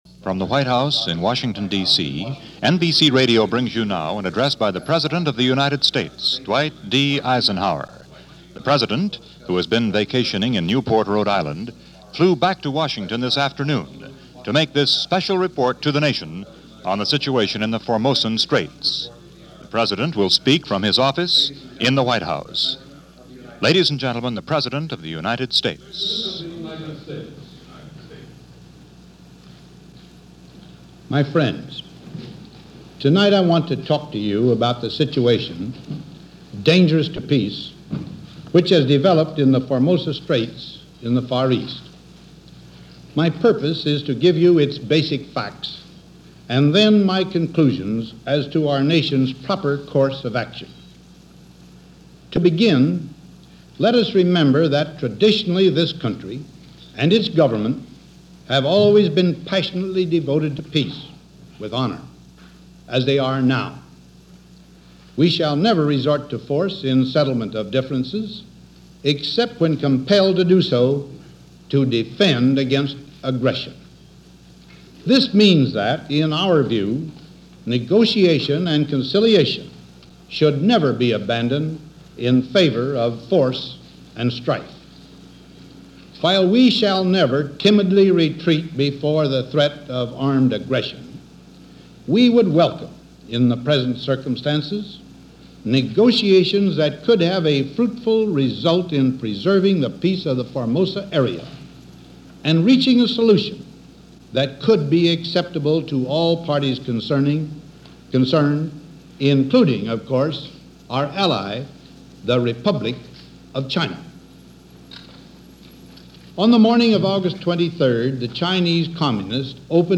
September 11, 1958 – President Eisenhower – Address to Nation over situation in Formosa – Gordon Skene Sound Collection –